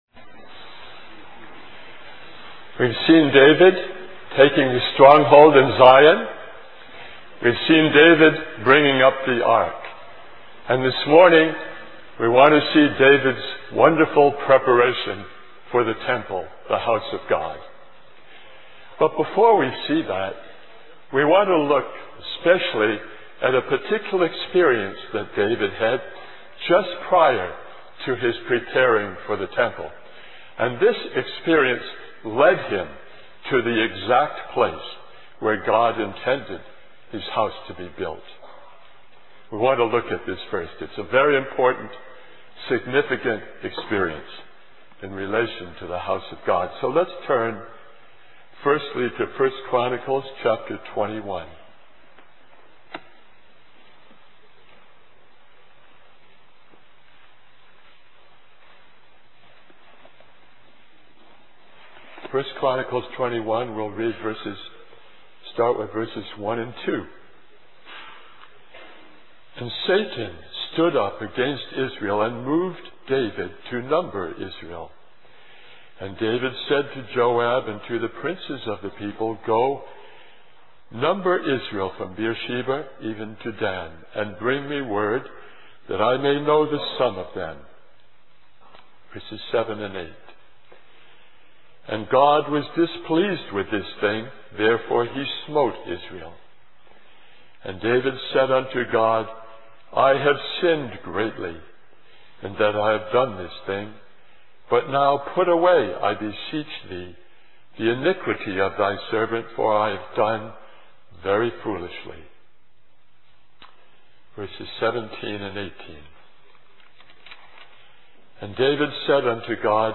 A collection of Christ focused messages published by the Christian Testimony Ministry in Richmond, VA.
Christian Family Conference